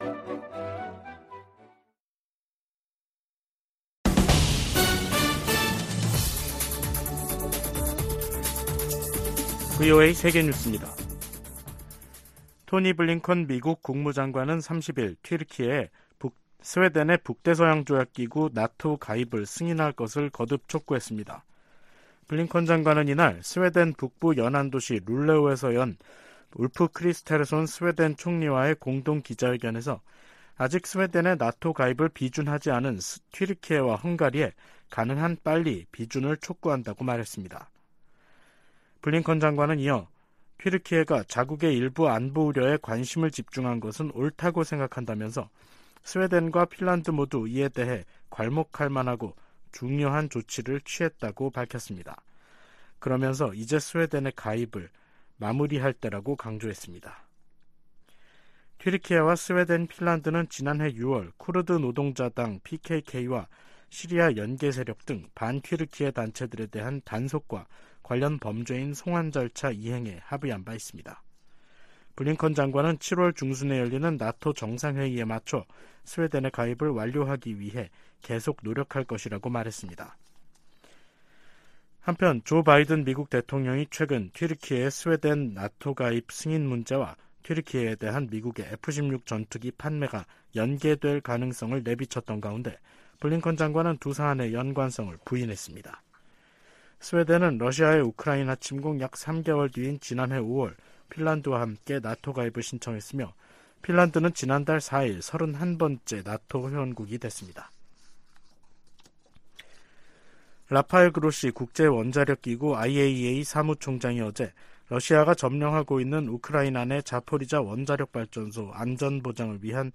VOA 한국어 간판 뉴스 프로그램 '뉴스 투데이', 2023년 5월 31일 3부 방송입니다. 북한은 31일 정찰위성 발사가 엔진고장으로 실패했다고 발표했습니다. 폴 라캐머라 한미연합사령관 겸 주한미군사령관은 한국에 대한 미국의 방위 공약이 철통같다며 이를 의심하지 말아 달라고 당부했습니다. 세계 각국의 여성 인권 상황을 심의하는 유엔 기구가 중국에 탈북 여성들의 지위를 정상화하고 기본적인 권리를 보장할 것을 권고했습니다.